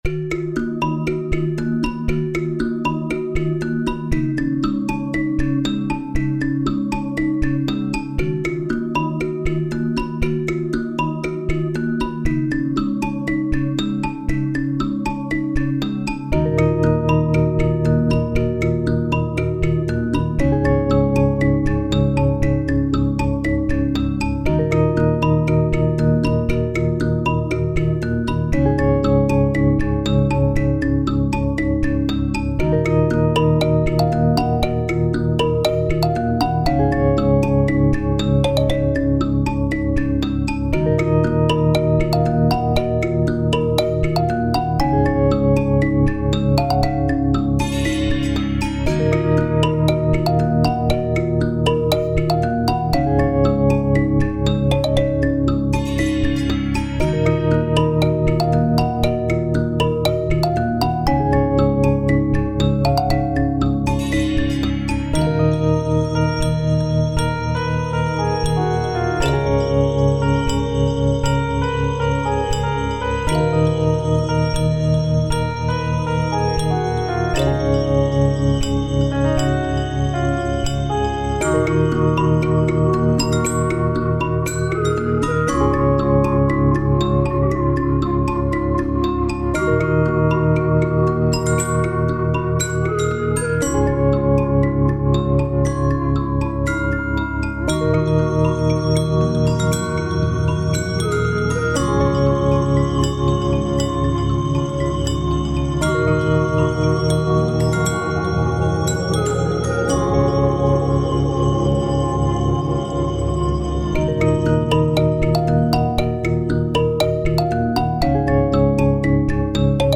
オーガニックな音色を多用し、淡々とした雰囲気と寂しさが混じり合ったような森のフィールド楽曲となっている。
タグ: フィールド楽曲 不思議/ミステリアス 寂しい/悲しい 暗い 森 コメント: 落葉した木々をテーマにした楽曲。